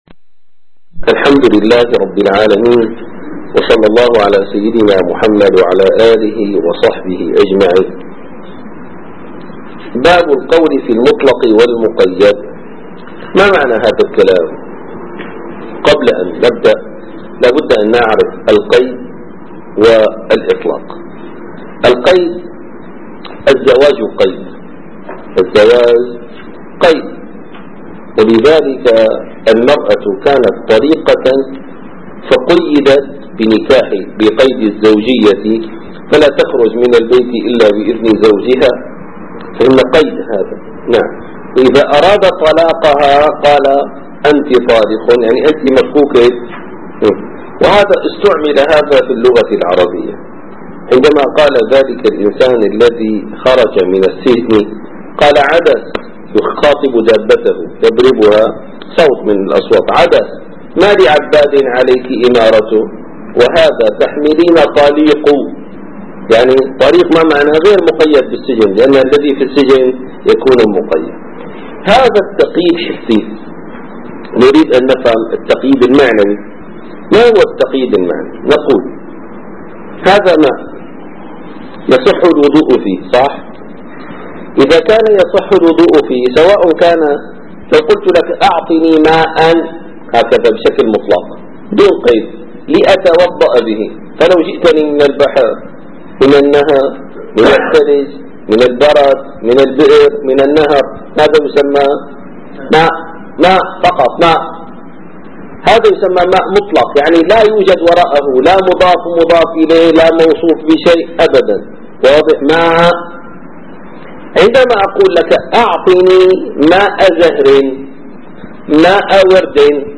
- الدروس العلمية - شرح كتاب اللمع للإمام الشيرازي - الدرس السابع عشر: باب القول في المطلق والمقيد (26) ص 101 - 103